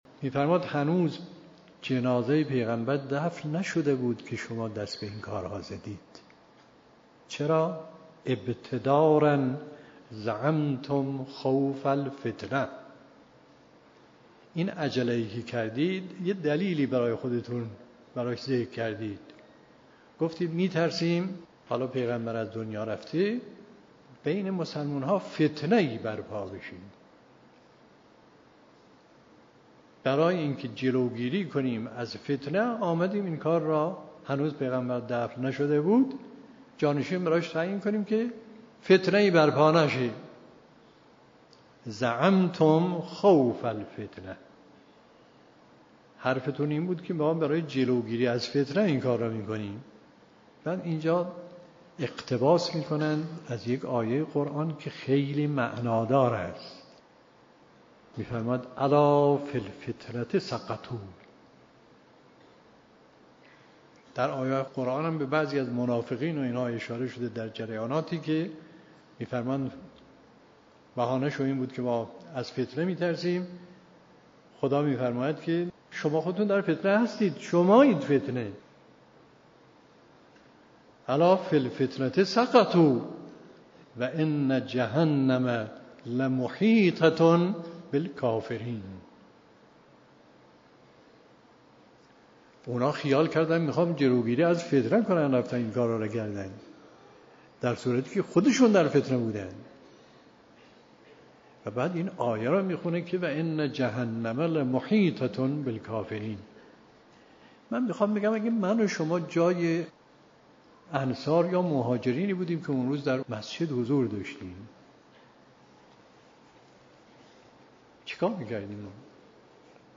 به گزارش خبرگزاری حوزه، به مناسبت ایام فاطمیه، گزیده‌ای از بیانات مرحوم آیت الله مصباح در رابطه با خطبه فدکیه حضرت زهرا سلام الله علیها تقدیم شما فرهیختگان می‌شود.